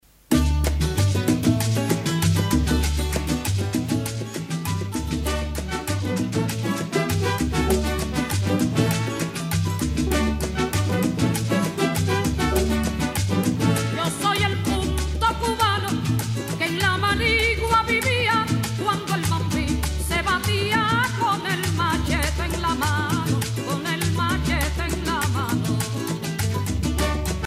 Sancti Spiritus, Cuba
Pièce musicale inédite